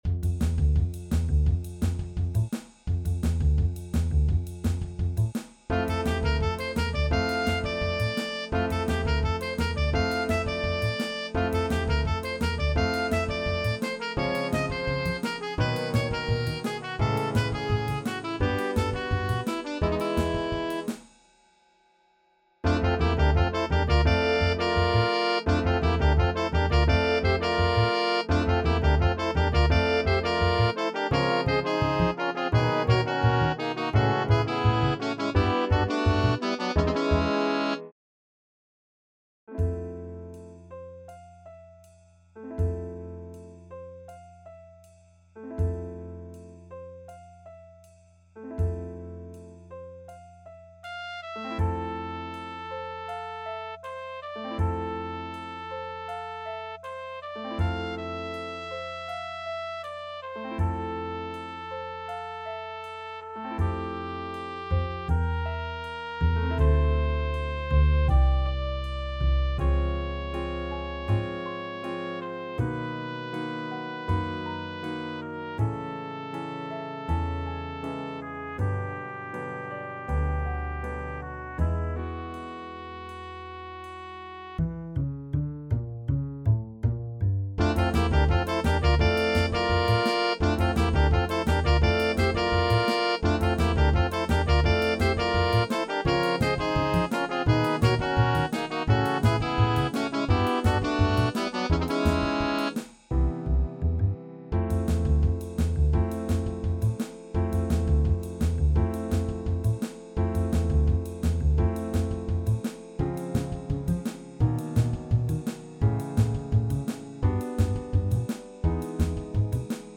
All audio files are computer-generated.
Trumpet, Sax, Trombone, Piano, Bass, Drums